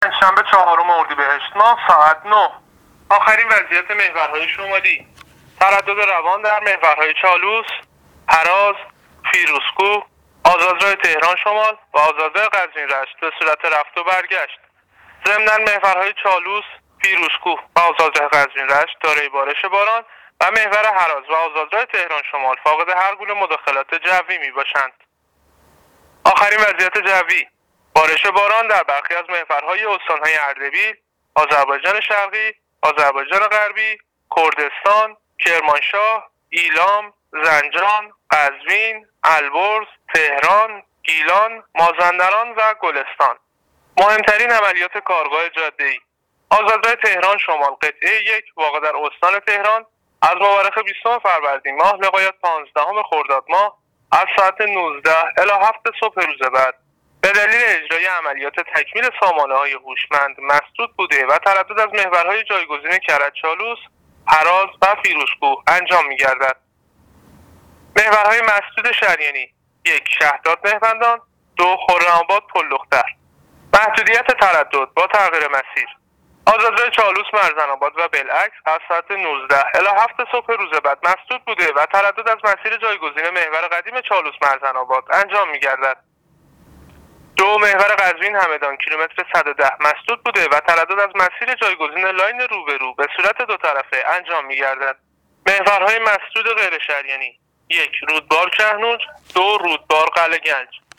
گزارش رادیو اینترنتی از آخرین وضعیت ترافیکی جاده‌ها تا ساعت ۹ چهارم اردیبشهت ۱۳۹۹